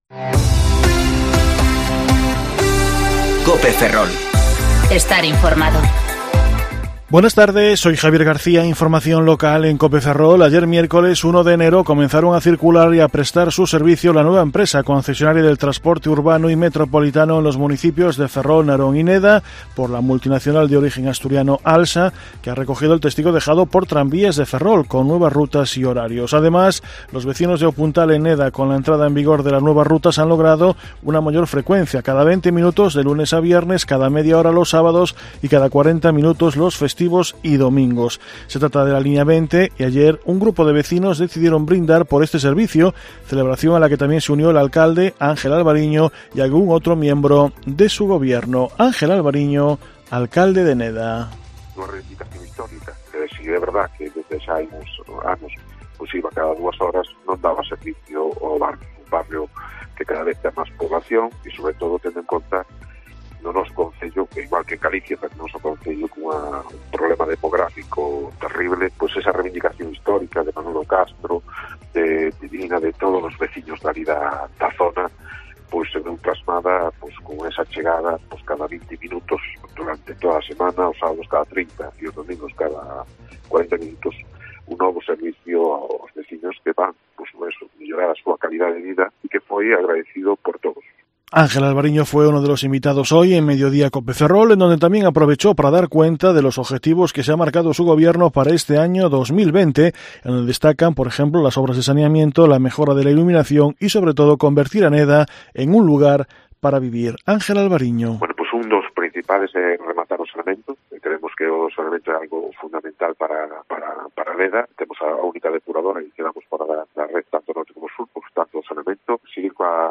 Informativo Mediodía COPE Ferrol - 2/1/2020 (De 14.20 a 14.30 horas)